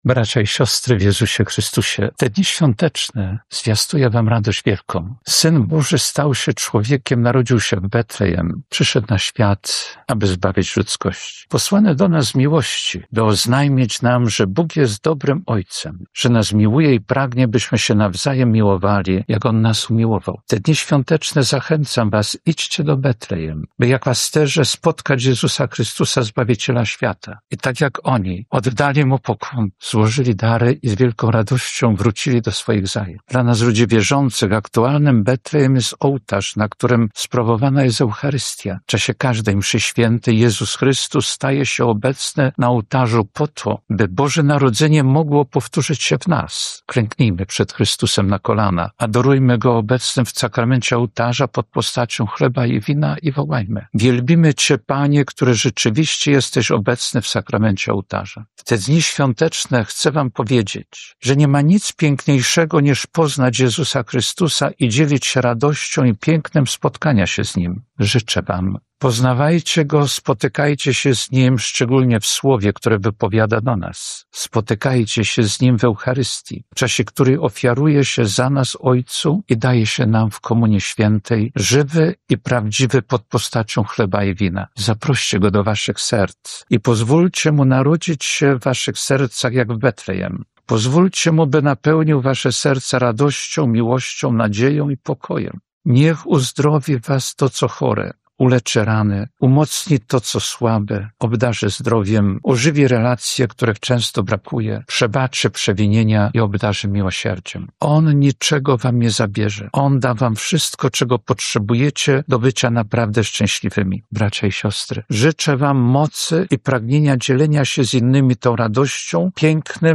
Świąteczne orędzie biskupa Jerzego Mazura